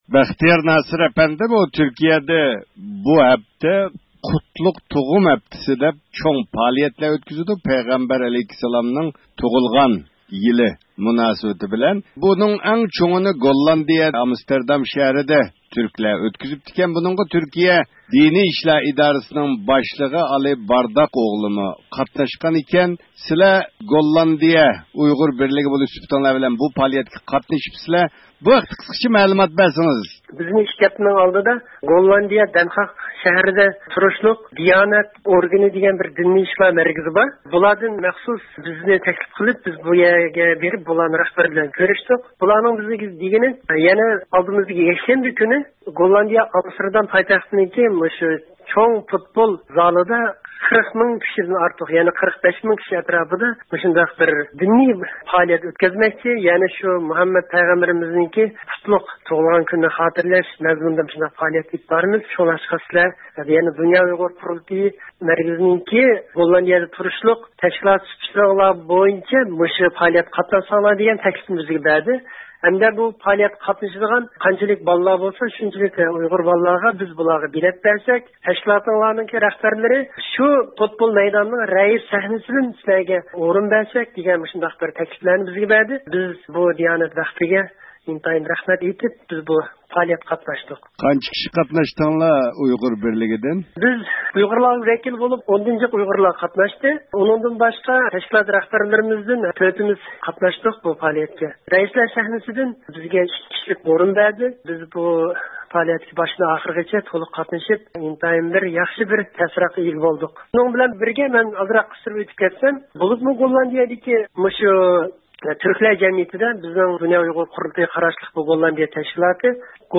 بۇ ھەقتە گوللاندىيىدىكى ئۇيغۇر پائالىيەتچىلىرى بىلەن تېلېفون زىيارىتى ئېلىپ باردۇق.